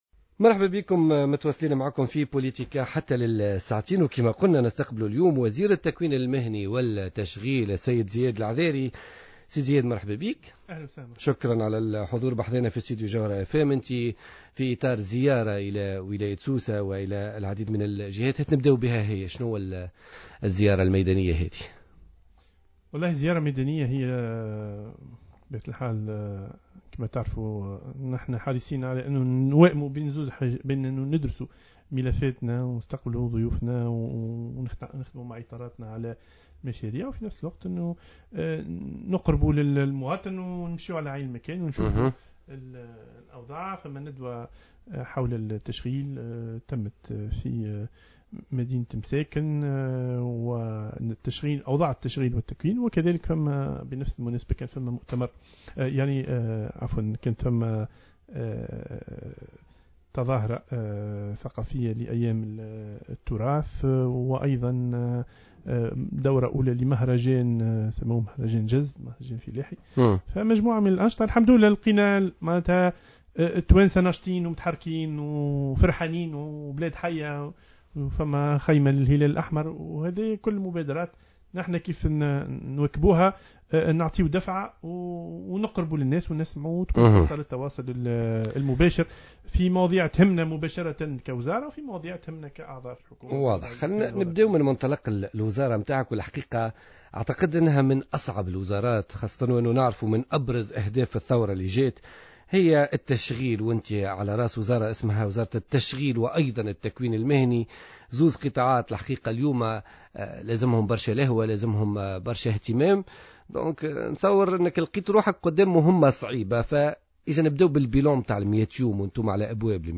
قال وزير التشغيل والتكوين المهني زياد العذاري اليوم الاثنين 11 ماي 2015 في برنامج بوليتيكا على "جوهرة أف ام" أن التكوين المهني في تونس مفتوح على الدكتوراه.